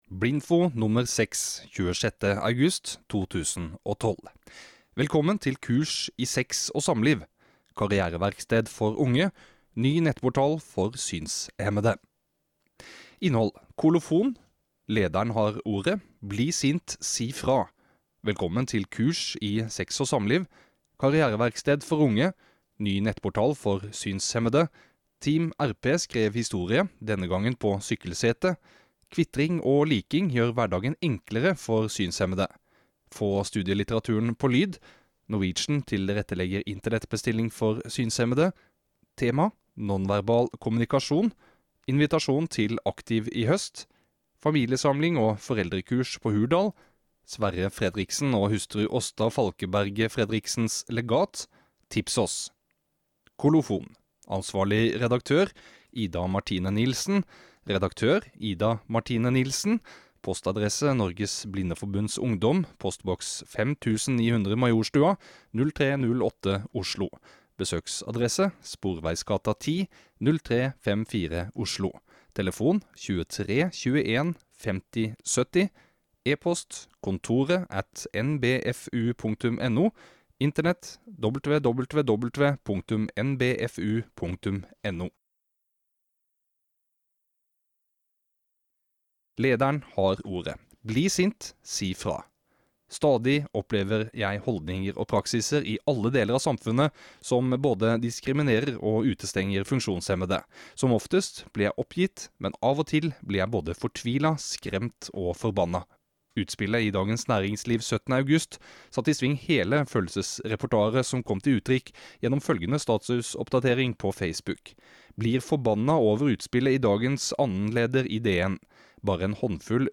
Denne kan lastes ned i både word-format og innlest MP3-fil.